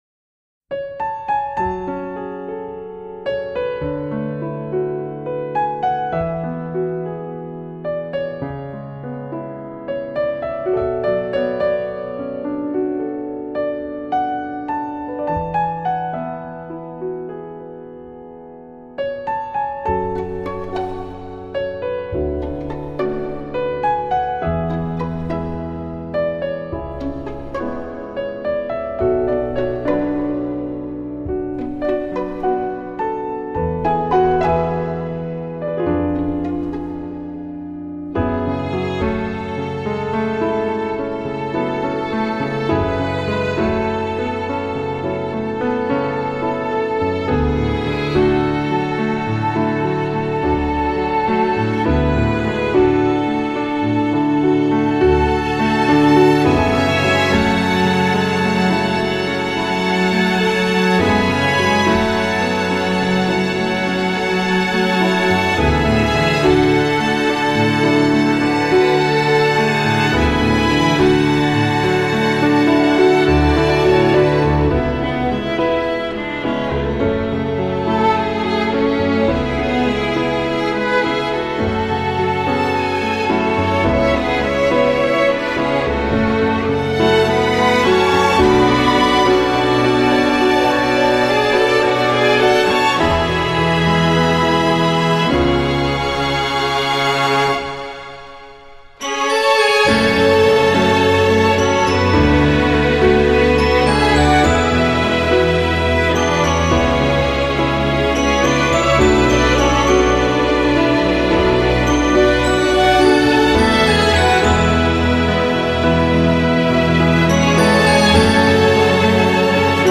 天籁钢琴 你是第9655个围观者 3条评论 供稿者： 标签：, ,
悠扬但感伤的旋律轻柔得像一个美梦，仿佛真能感到有一阵微风从脸颊轻轻拂过，钢琴独有的典雅和孤寂将剧中场景的气氛推向顶点。